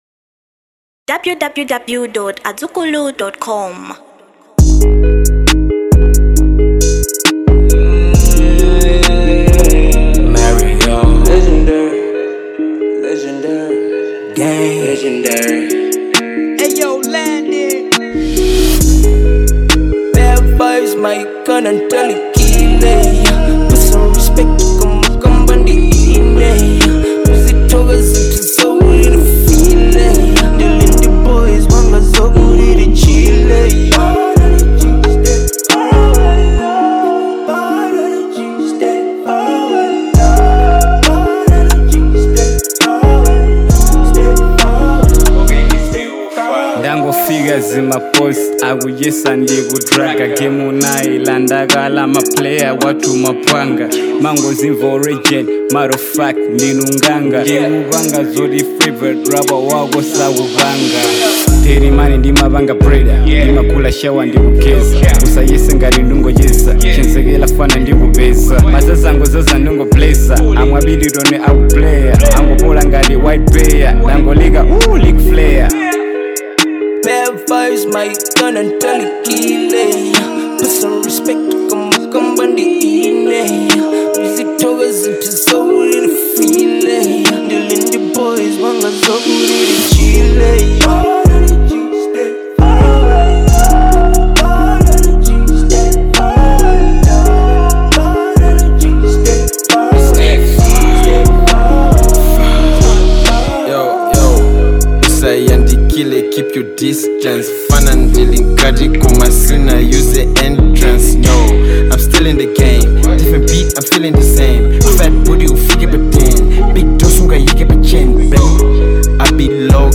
Genre HIP HOP